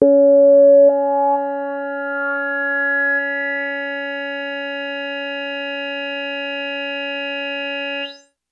描述：通过Modular Sample从模拟合成器采样的单音。
标签： CSharp5 MIDI音符-73 罗兰木星-4 合成器 单票据 多重采样
声道立体声